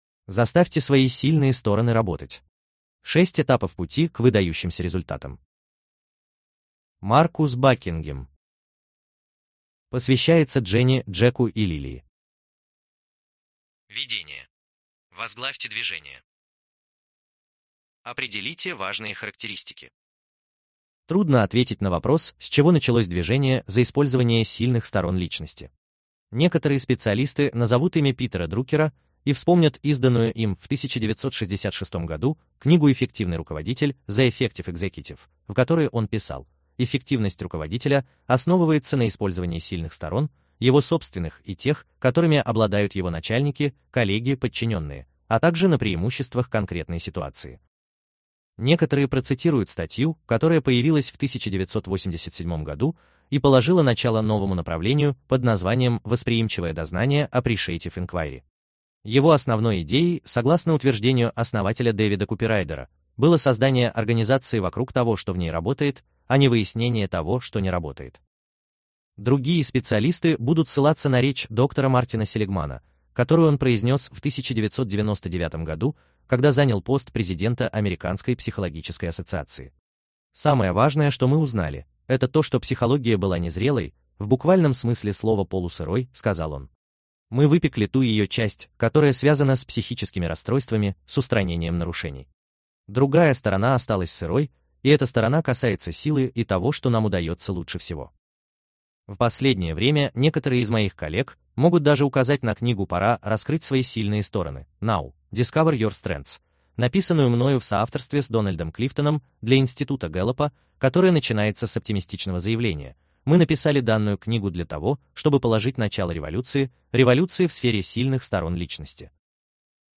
Книга озвучена искусственным интеллектом.